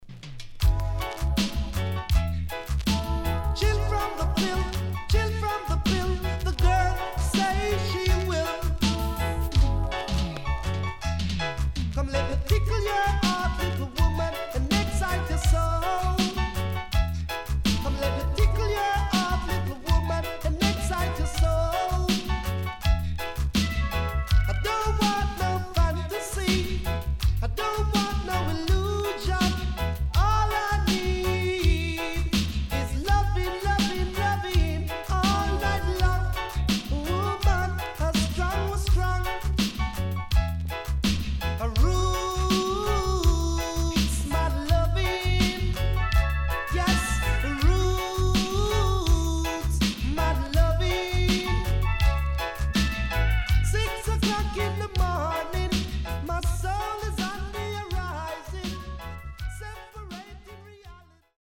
HOME > REISSUE USED [DANCEHALL]
SIDE B:少しノイズ入りますが良好です。